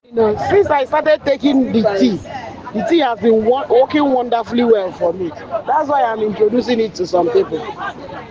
Verified Customer
Testimonial 3